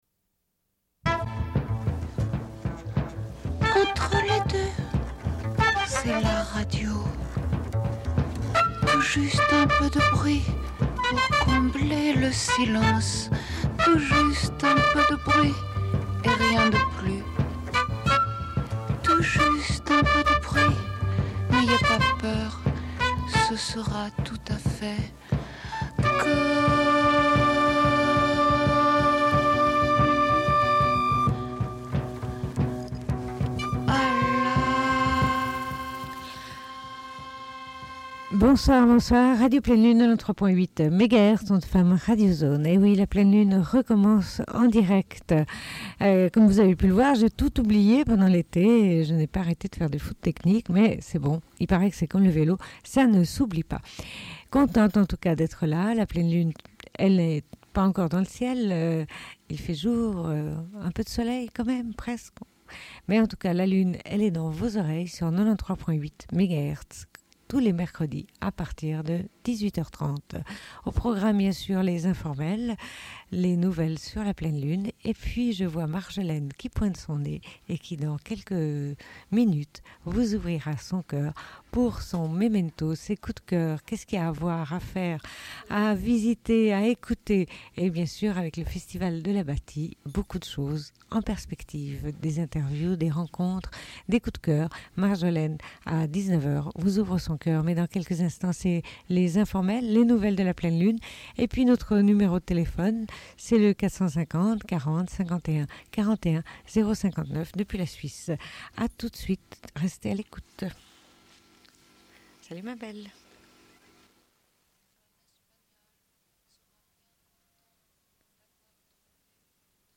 Bulletin d'information de Radio Pleine Lune du 09.09.1998
Une cassette audio, face B